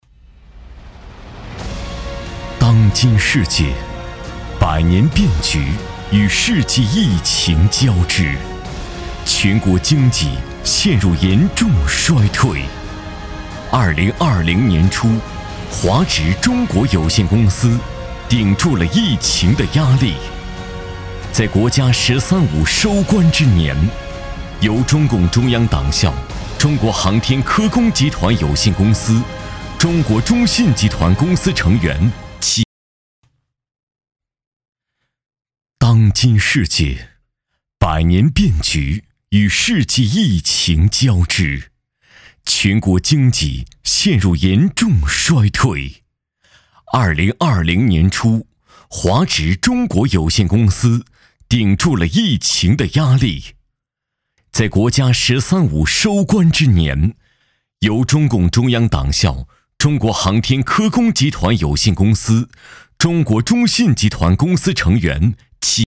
宣传片_纪录片配音网_广告在线配音制作网站-星晏配音网
男8号（大气、时尚）
男8-大气企宣百年变革.mp3.mp3